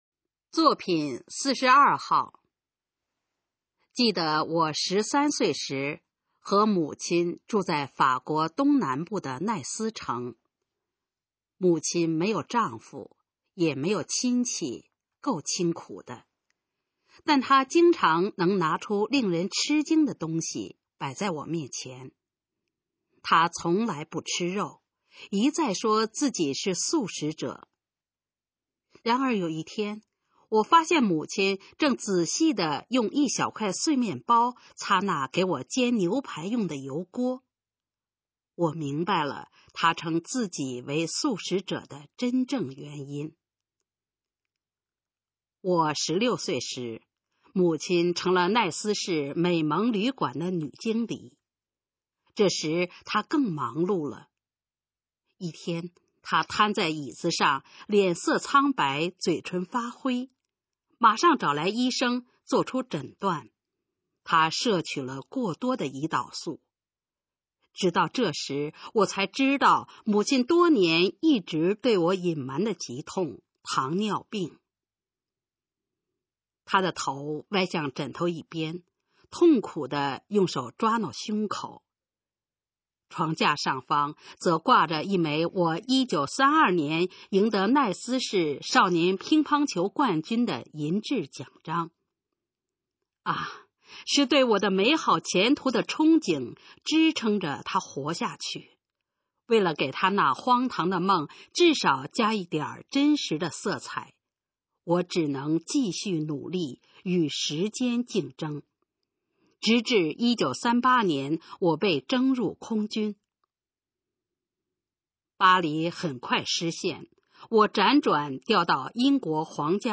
《我的母亲独一无二》示范朗读_水平测试（等级考试）用60篇朗读作品范读